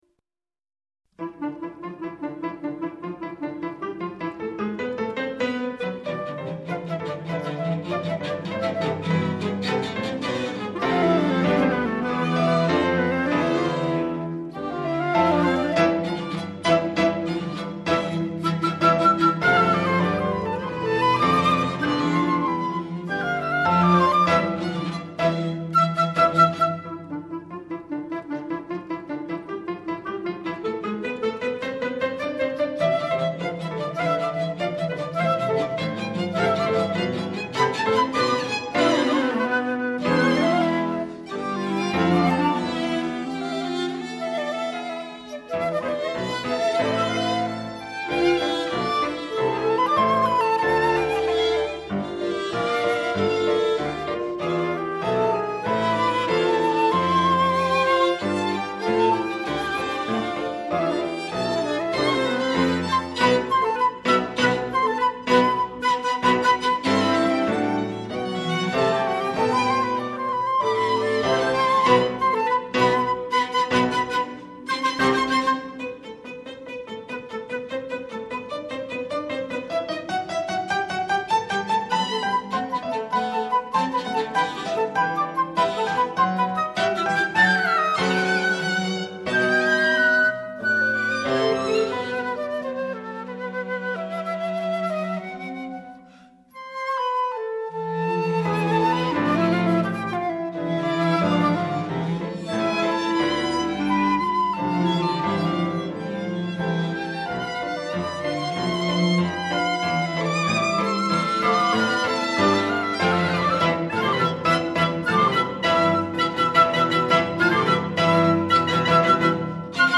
per flauto, clarinetto, violino, violoncello e pianoforte